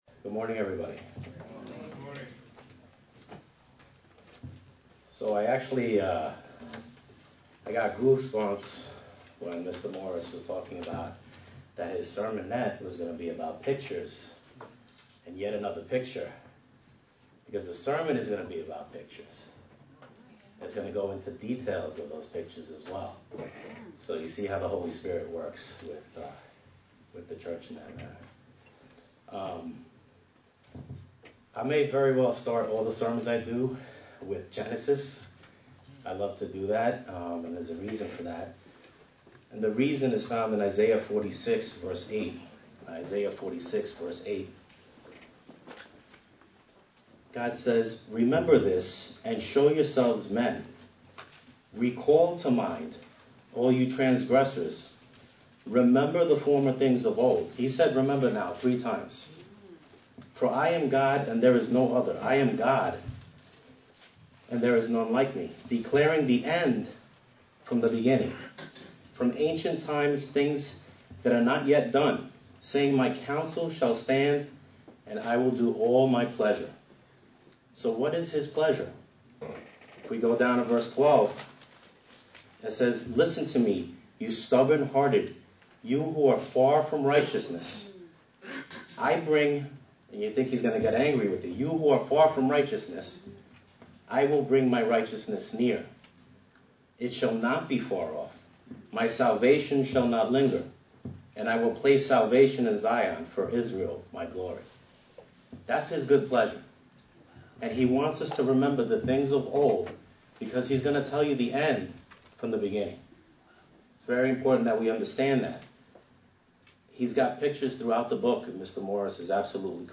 Given in New York City, NY
UCG Sermon Studying the bible?